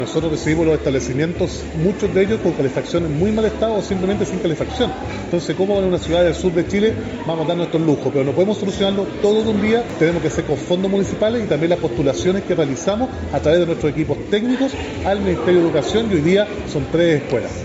Tal como lo confirmó el alcalde, Roberto Neira, al referirse al Plan Frío Cero.